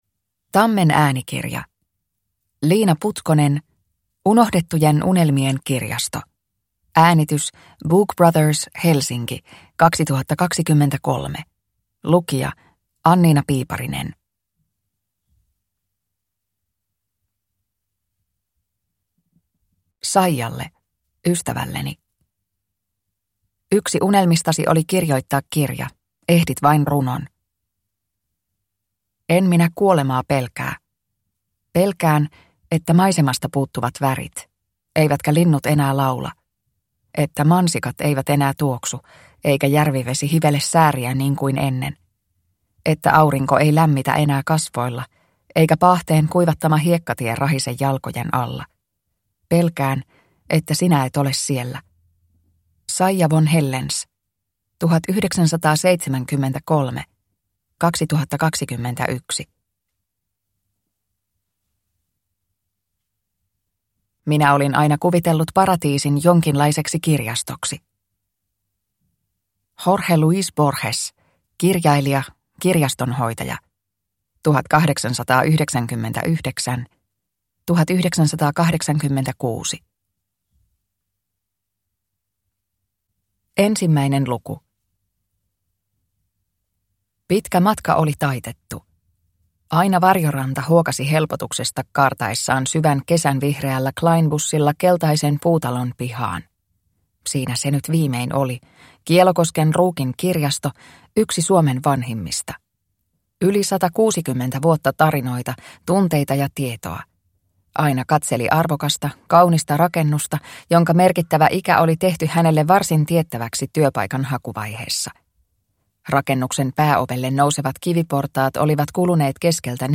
Unohdettujen unelmien kirjasto – Ljudbok – Laddas ner